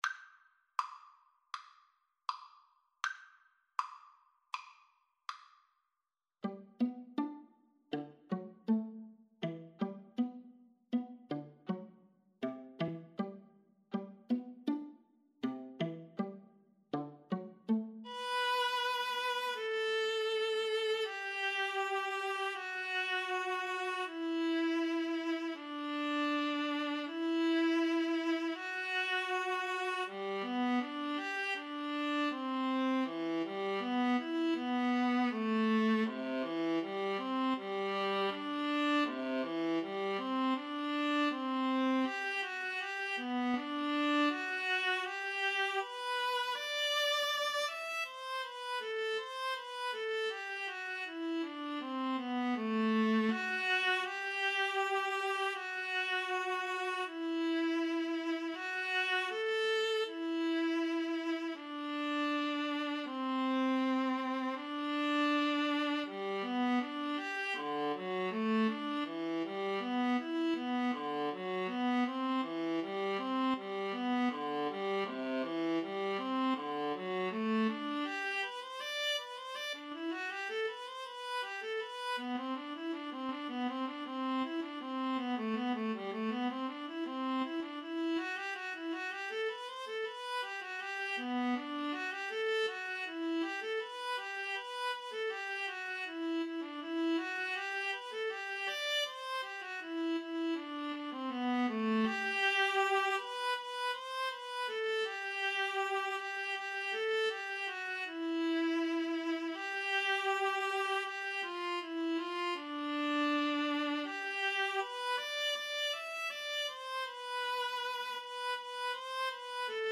Classical